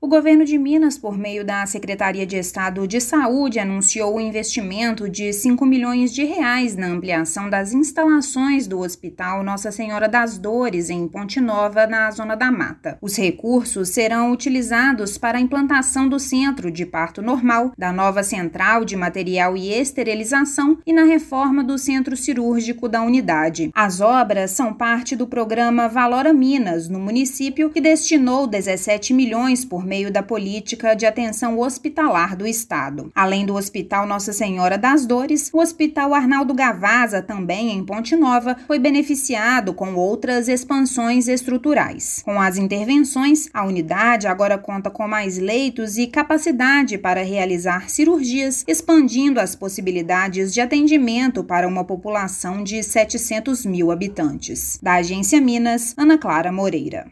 Programa Valora Minas já repassou mais de R$ 17 milhões para os hospitais Nossa Senhora das Dores e Arnaldo Gavazza na cidade. Ouça matéria de rádio.